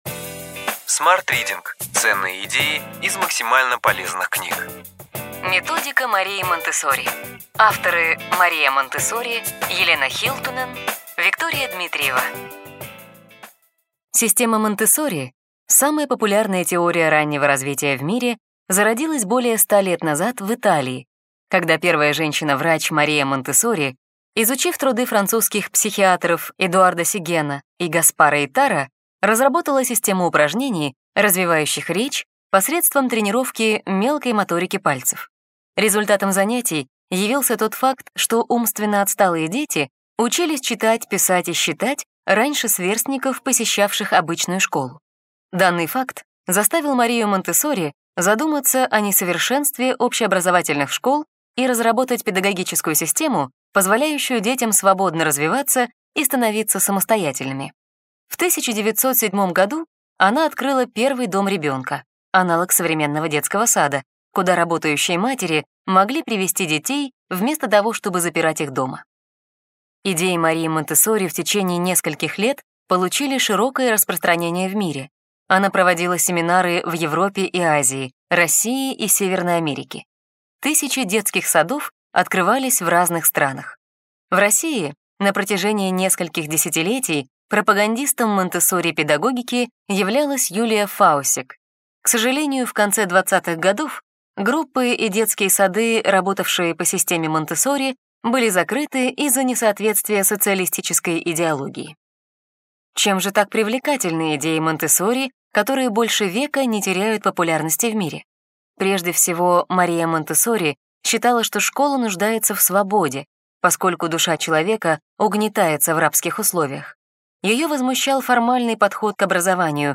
Аудиокнига Ключевые идеи книги: Методика Марии Монтессори.